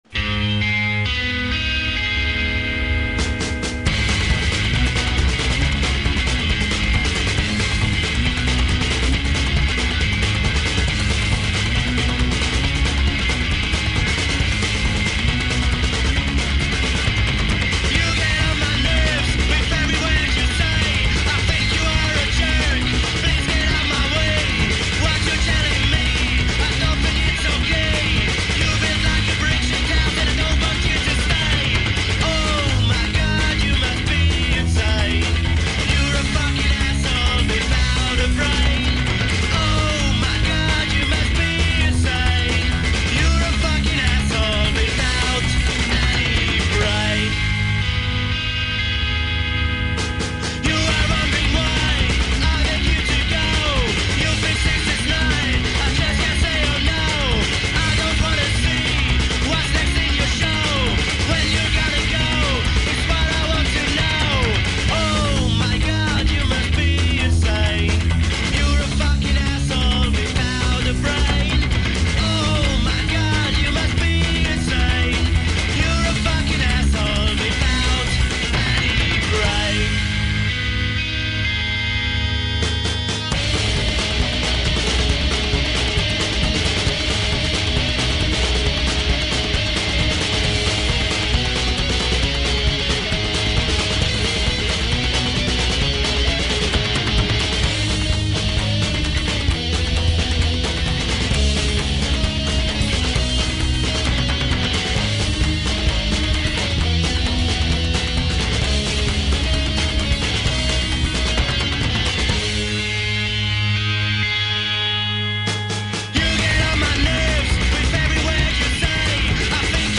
Ca sonne autralien :D
Je l'est eu sur une map maison du jeux postal 2    je tien a dir qu elle était dans un pack foutoir ou tout naturelement le nom du morceau n est pas indiqué (re enregistré en utilisan la sortit ligne du pc)